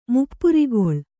♪ muppurigoḷ